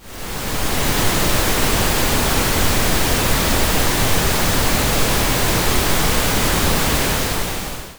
Ce type de bruit, à la différence du bruit blanc, a un spectre dont l’énergie diminue à mesure que la fréquence augmente. L’ atténuation dans le bruit rose est, plus précisément, de 3 dB par octave.
ici quelques secondes audio de bruit rose :
pink.wav